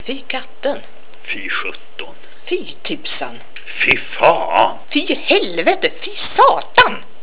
Для прослушивания или скачивания звукового файла, содержащего произношение приведенных примеров, пожалуйста, нажмите на название соответствующего раздела.